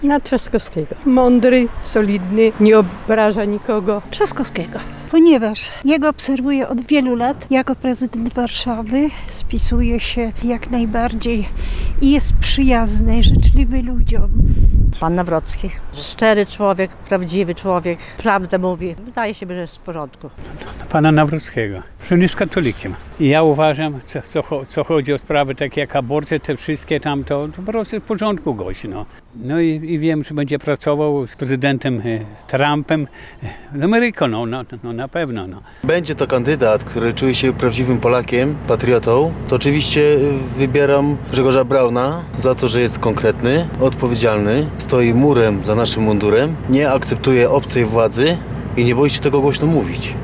– Rafał Trzaskowski, Karol Nawrocki i Grzegorz Braun – na te osoby w wyborach prezydenckich zamierzają głosować przechodnie, których spotkaliśmy dziś na ulicach Suwałk. Zdecydowana większość wskazywała trzech, wymienionych wcześniej kandydatów.